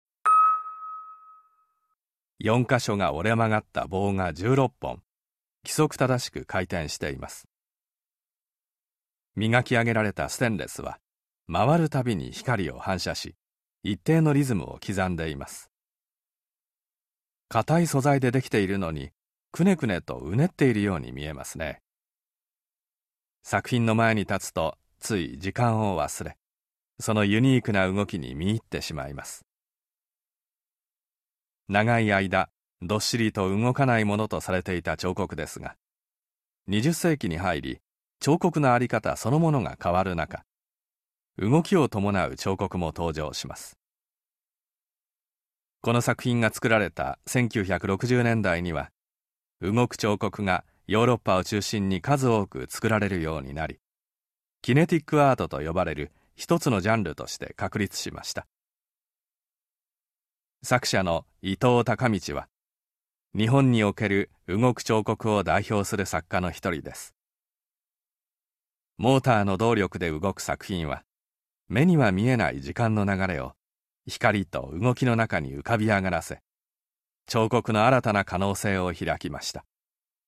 箱根 彫刻の森美術館 THE HAKONE OPEN-AIR MUSEUM - 音声ガイド - 伊藤隆道 16本の回転する曲がった棒 1969年